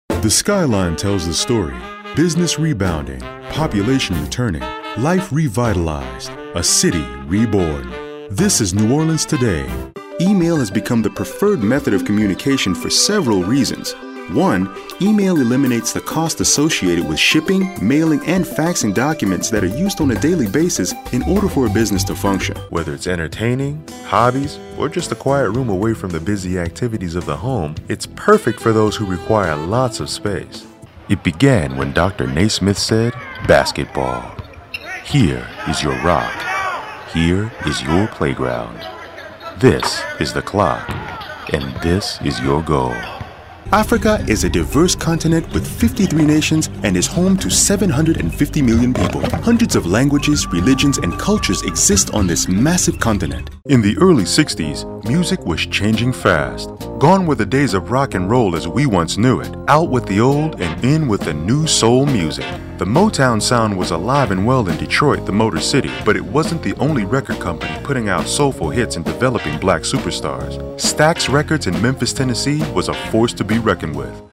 Deep, smooth sophistication with a touch of natural appeal, dark, ominous, movie trailer, smooth, sophisticated, natural
Sprechprobe: Industrie (Muttersprache):